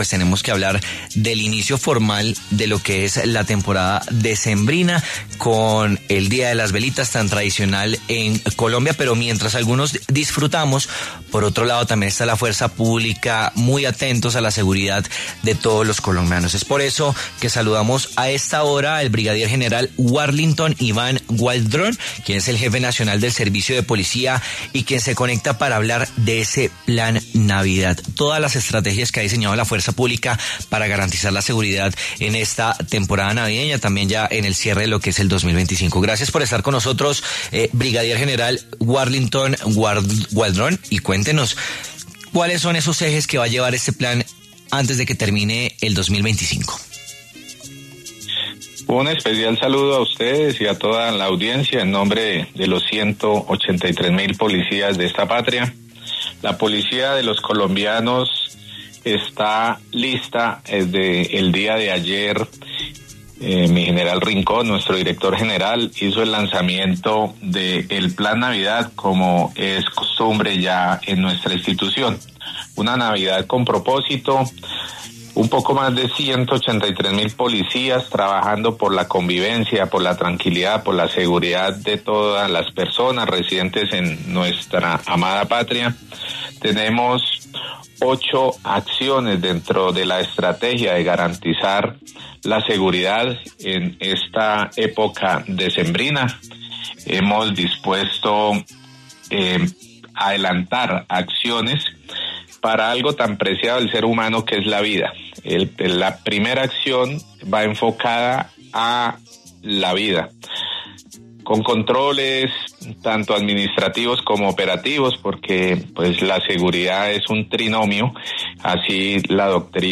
El brigadier general Wharlintón Iván Gualdrón, vocero de la Policía Nacional, explicó en W Fin De Semana algunos puntos que se enmarcan en la estrategia ‘Plan Navidad’.